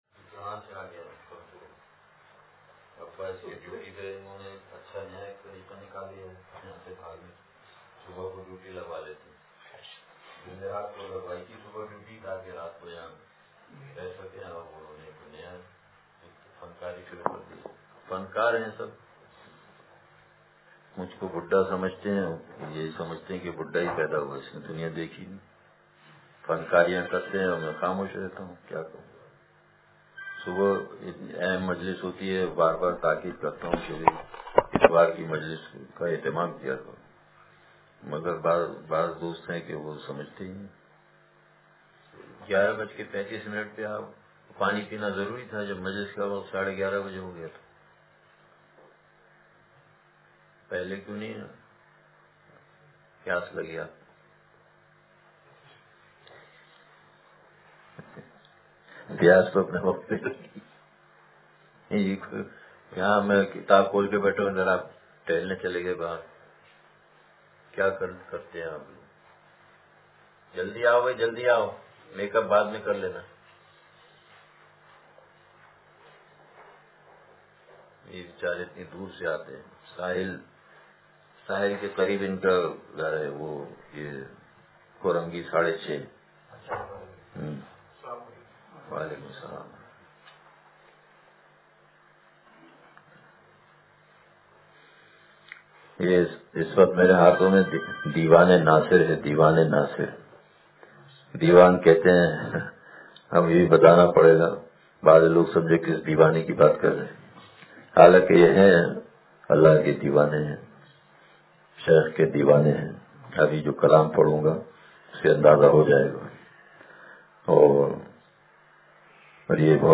دیوانِ ناصر سے اشعار – یہی شغل ملتا ہمیں دو جہاں میں ملاقاتِ رب کی دعا کرتے رہتے – مجلس بروز اتوار